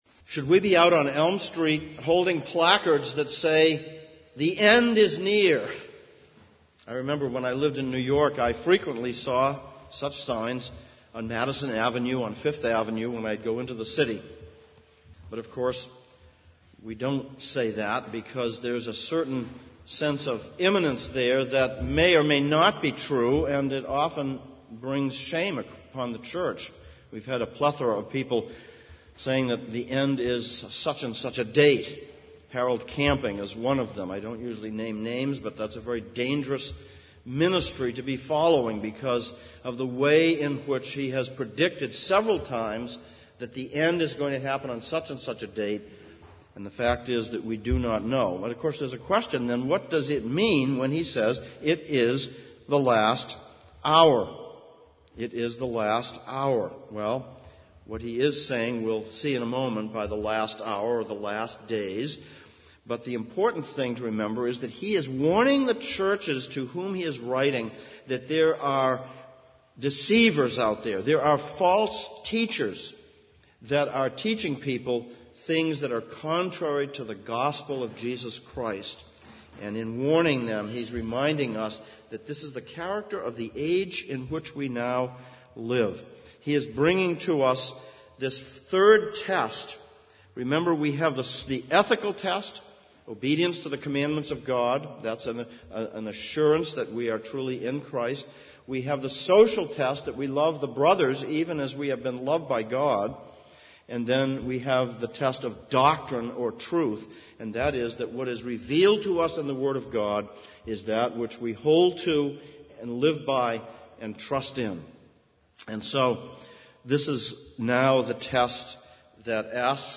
Series: Exposition on 1 John Passage: 1 John 2:15-29, Isaiah 8:1-22 Service Type: Sunday Morning Sermon